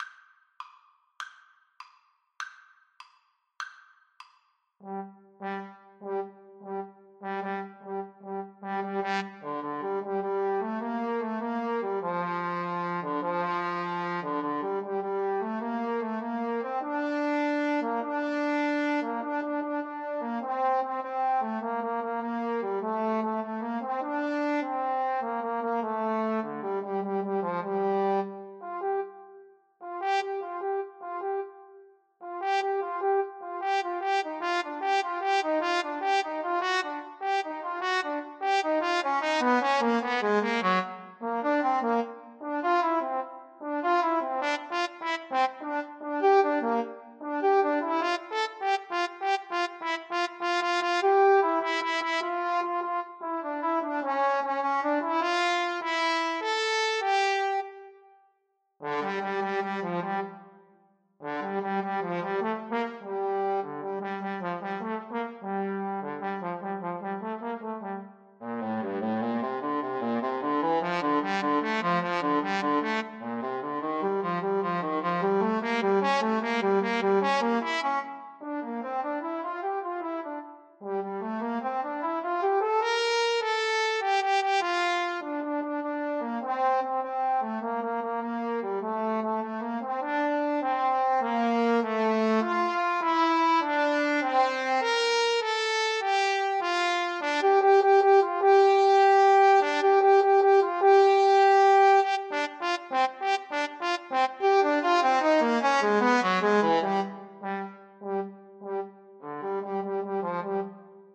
Trombone 1Trombone 2
Quick and menacing (. = c.100)
6/8 (View more 6/8 Music)
Classical (View more Classical Trombone Duet Music)